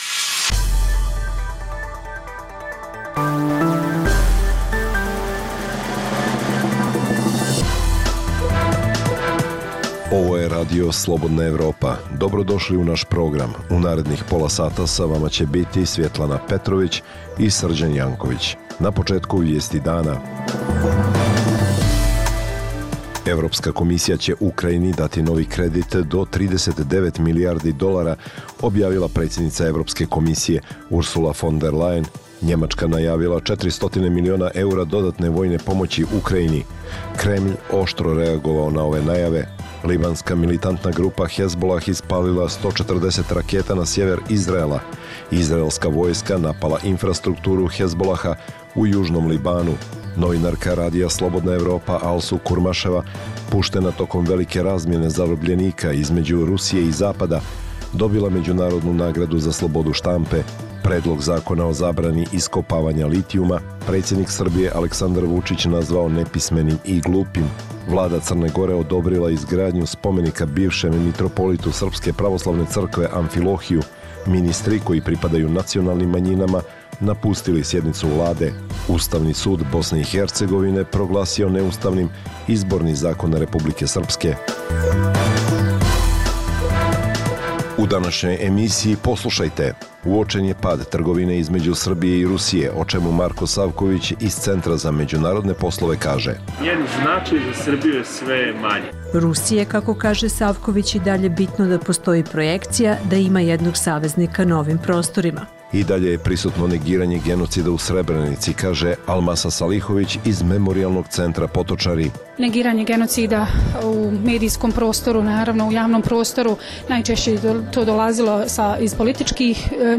Poslušajte Aktuelno, regionalnu radio emisiju
Dnevna informativna emisija Radija Slobodna Evropa o događajima u regionu i u svijetu. Vijesti, teme, analize i komentari.